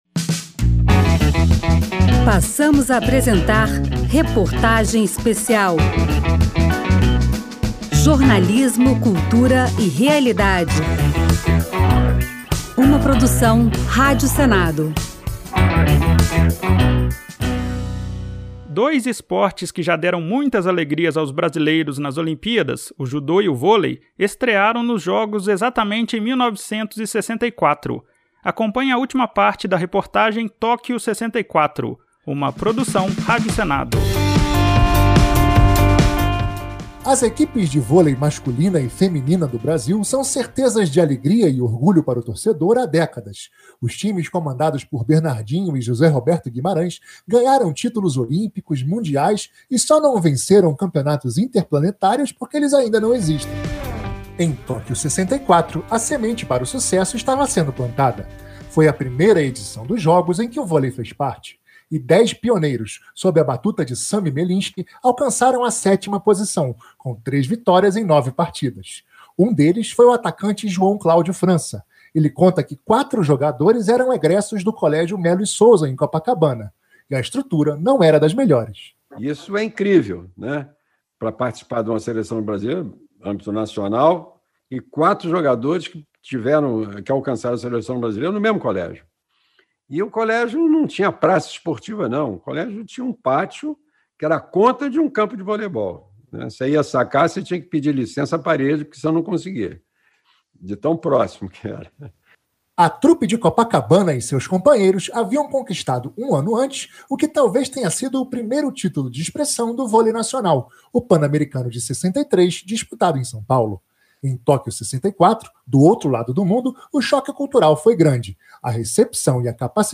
Reportagem Especial da Rádio Senado relembra histórias dos primeiros jogos olímpicos disputados no Japão
“Tóquio 64” traz entrevistas exclusivas com atletas brasileiros que participaram dos jogos.